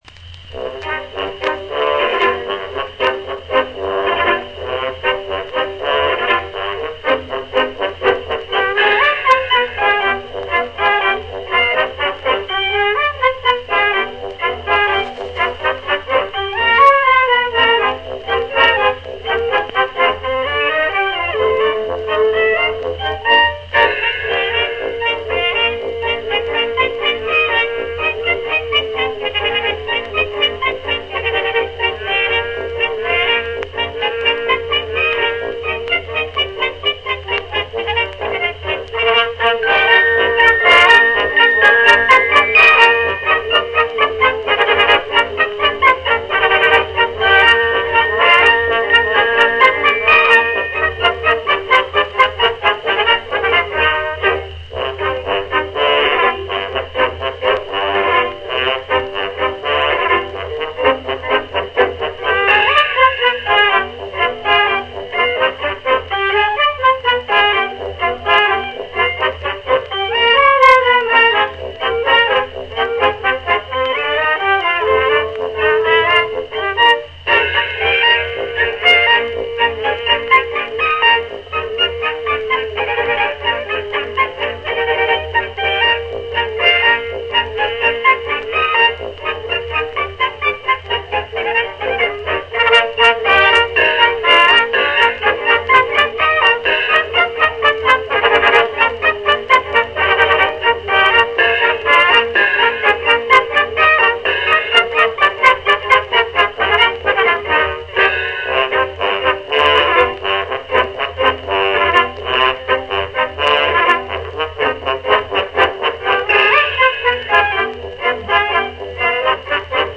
A British studio orchestra, conductor unknown.